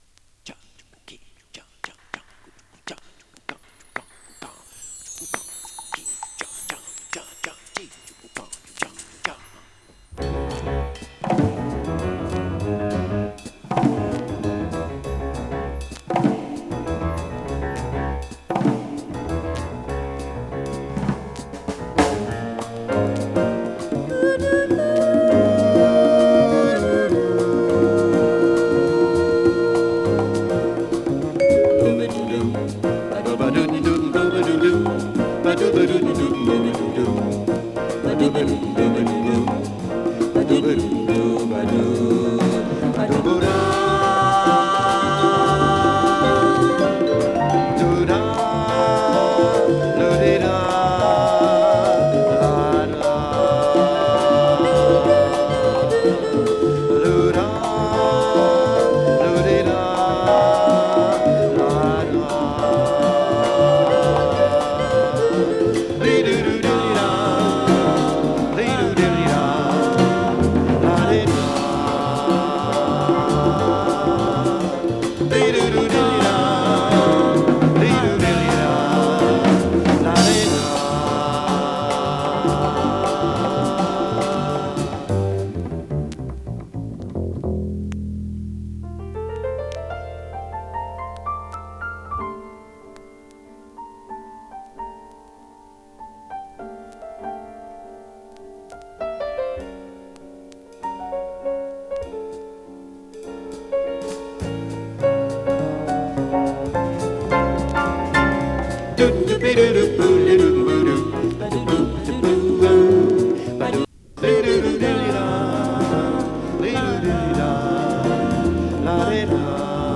＊時おり軽いチリ/パチ・ノイズ。